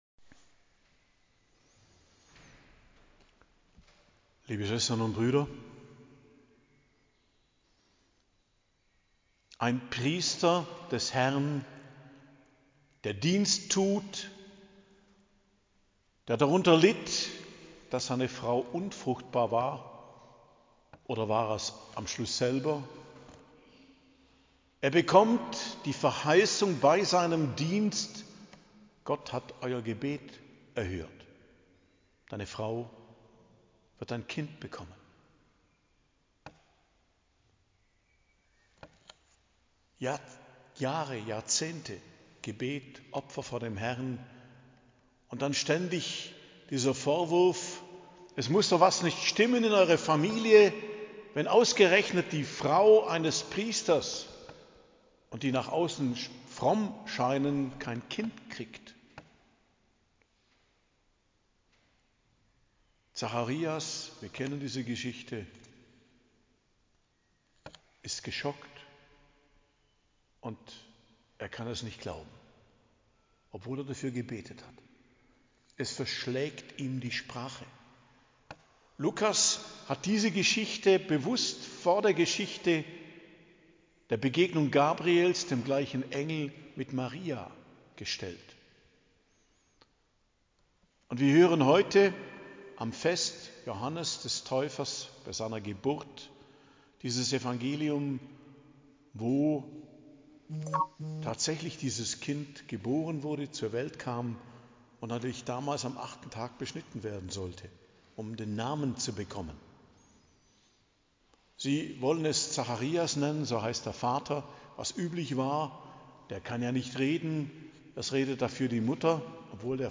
Predigt zum Hochfest der Geburt des Hl Johannes des Täufers, 24.06.2025